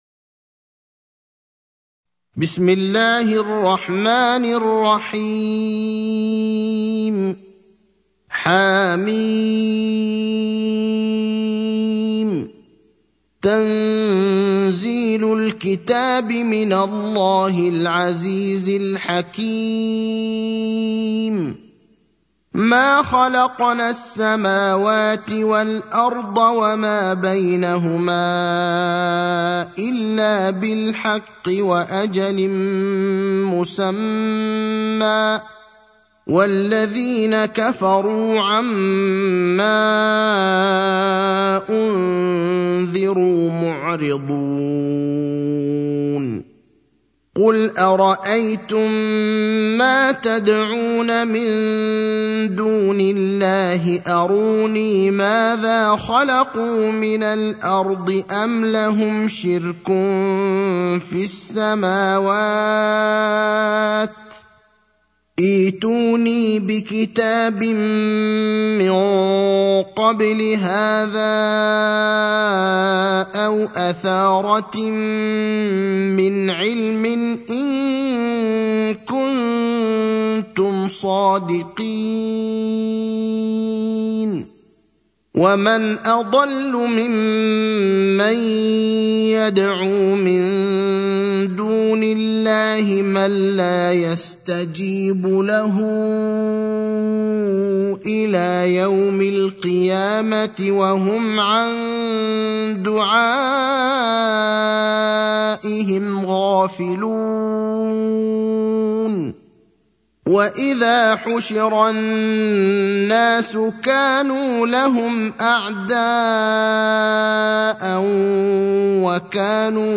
المكان: المسجد النبوي الشيخ: فضيلة الشيخ إبراهيم الأخضر فضيلة الشيخ إبراهيم الأخضر الأحقاف (46)